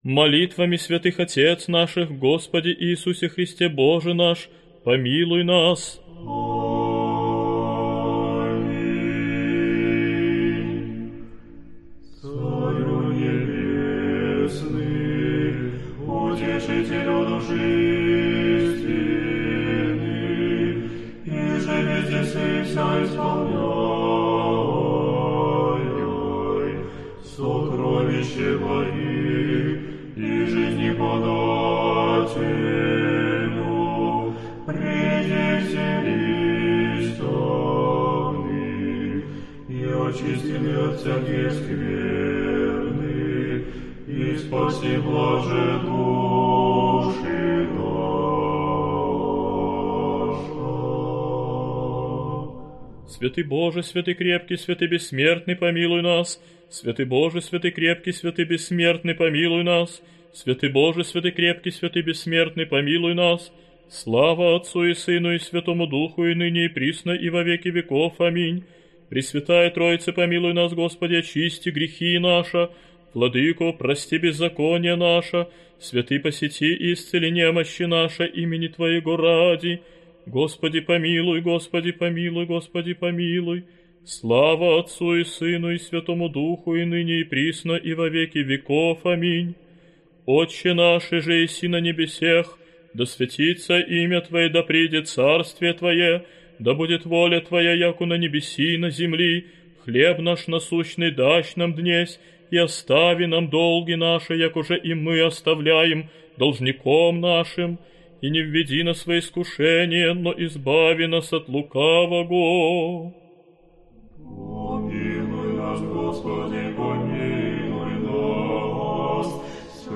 Аудиокнига Псалтирь пророка и царя Давида | Библиотека аудиокниг